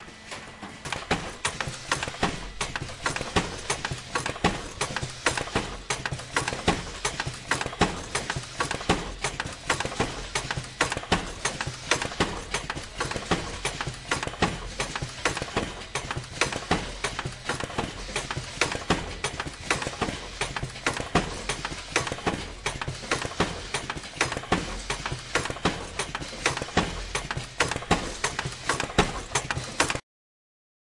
Toothing Machine
描述：A machine punching tooth spaces in a band saw with a crank punch while the band coil is advancing. There is a pause where no punches are made. The machine is punching hundreds of teeth per minute.Recorded with a Rode NT4 microphone and the Edirol R44 recorder.
标签： punch factory automatic sawtooth machine
声道立体声